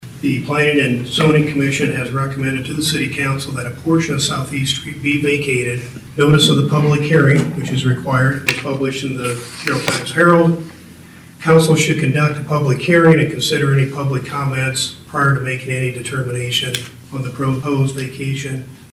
City Attorney Dave Bruner outlines the multi-step process.